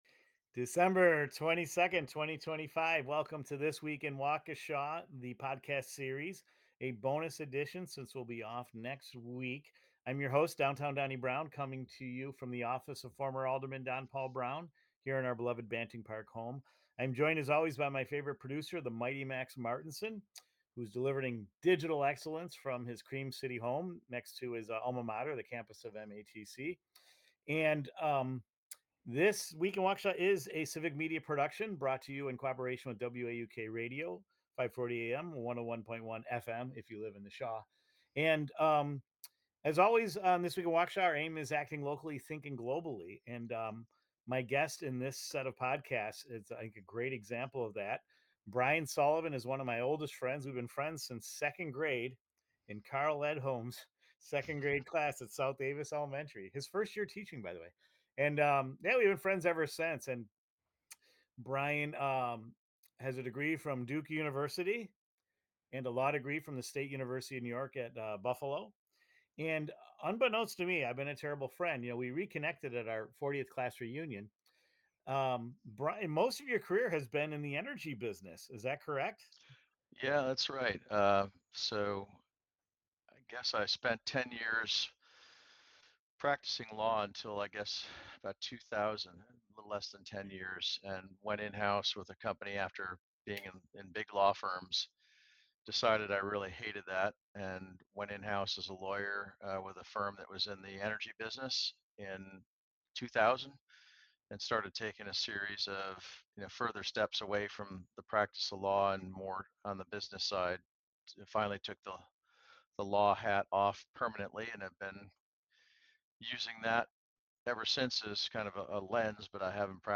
Broadcasting from the office of former Alderman Don Browne in Banting Park, the conversation explores metallurgical coal and steelmaking, Great Lakes industrial history, global supply chains, and the realities of operating an international business amid political uncertainty.